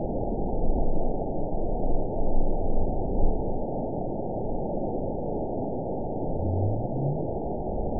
event 913820 date 04/21/22 time 23:14:01 GMT (3 years ago) score 9.54 location TSS-AB01 detected by nrw target species NRW annotations +NRW Spectrogram: Frequency (kHz) vs. Time (s) audio not available .wav